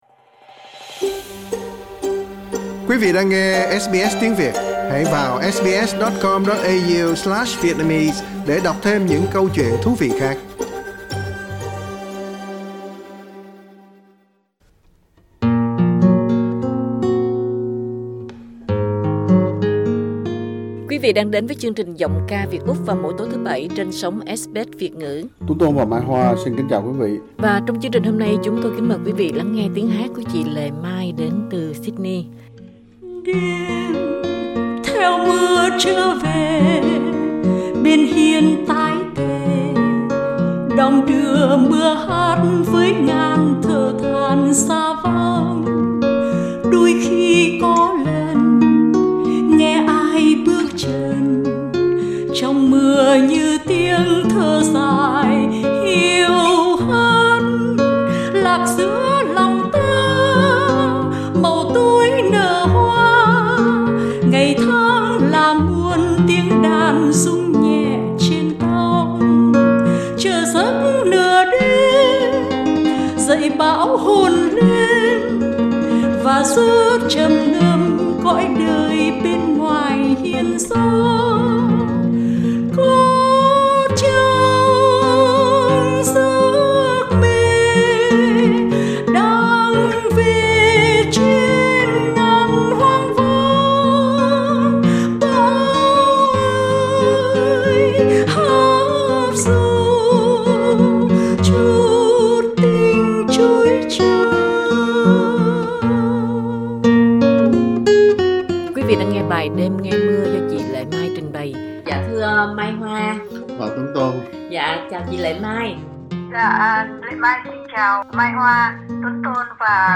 Hát unplugged chỉ với một cây đàn guitar
thu trực tiếp
Tiếng hát miên man như nước chảy, tiếng hát dịu dàng ve vuốt như cánh bướm vờn hoa, tiếng hát thảnh thơi thanh thỏa như chim non chuyền cành trong sớm mai rừng vắng